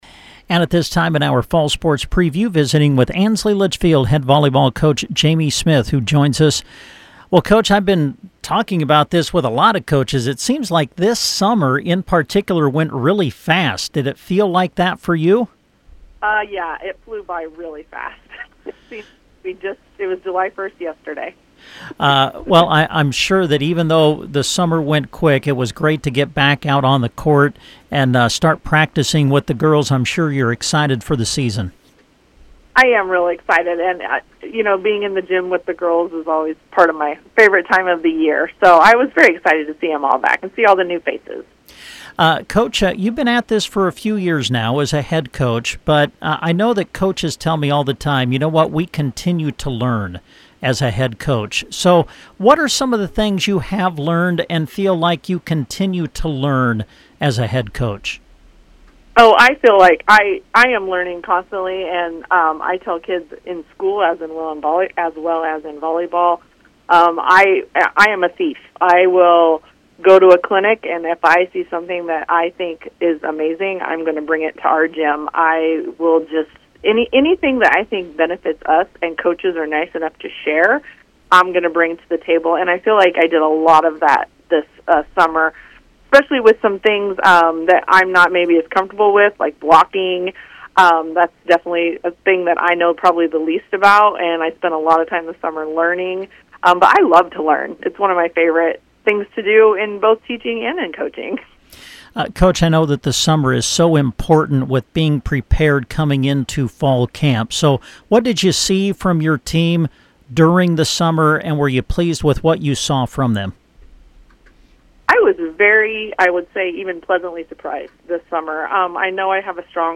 Ansley/Litchfield Volleyball Preview - Interview